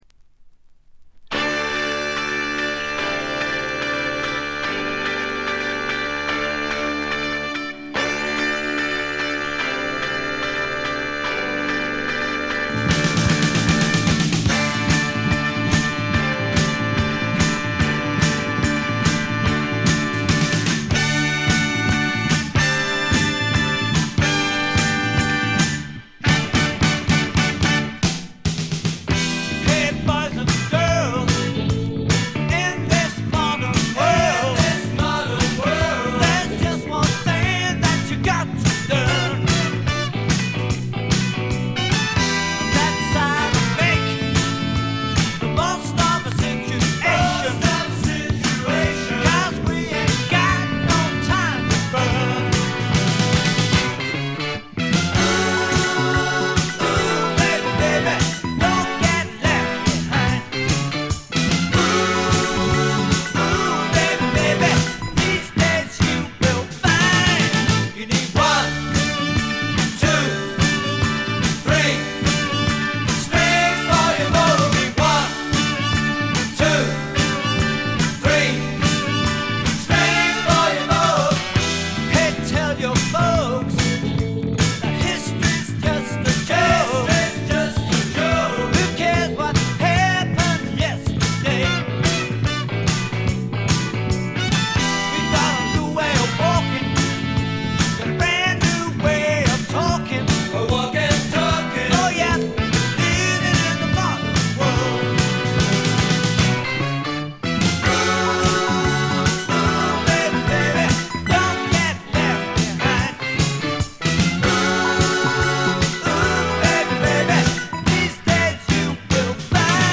二人のホーンセクションが実によく効いてます。
試聴曲は現品からの取り込み音源です。